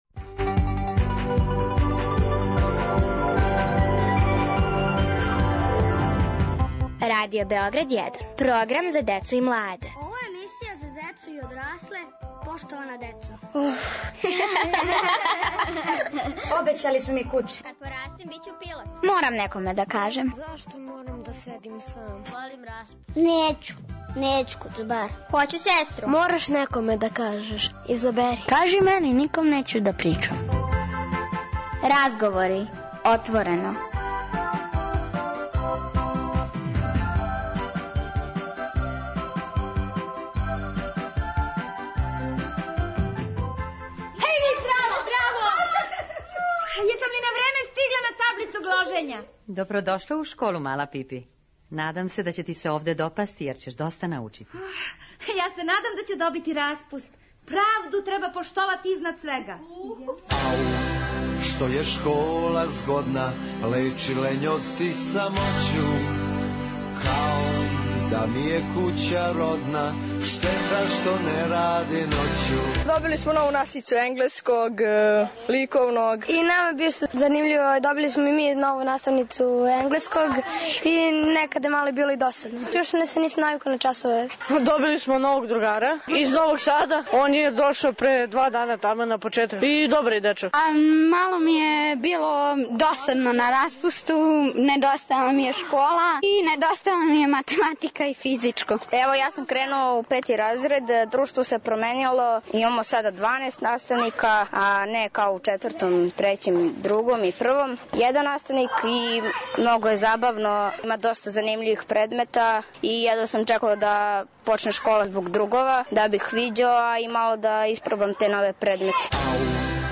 Разговори - отворено: У школу полазе деца али и родитељи и наставници. О томе отворено разговарају у емисији.